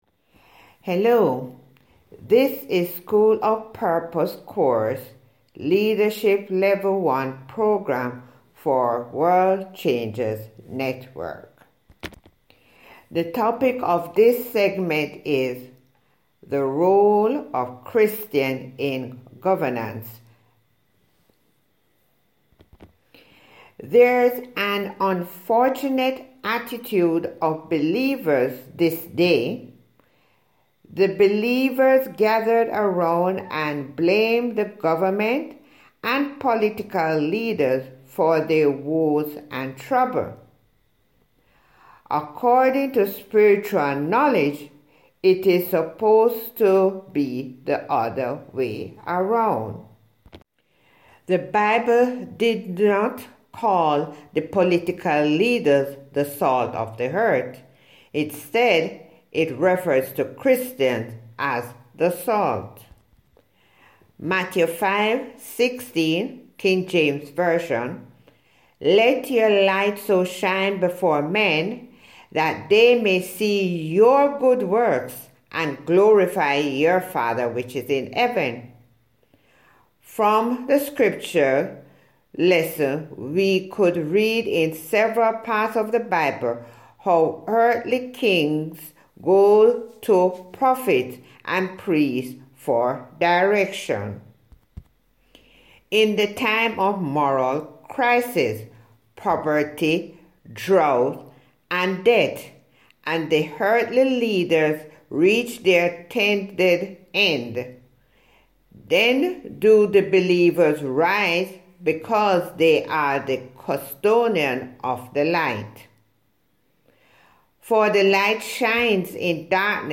Listen to Human Voice Reading Here: